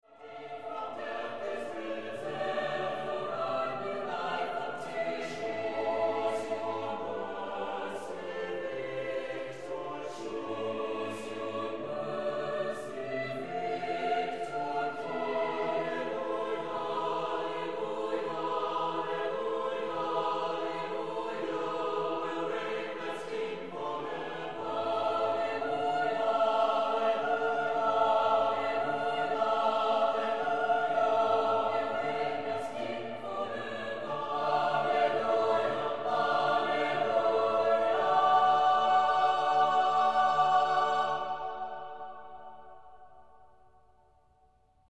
Voicing: SATB Double Choir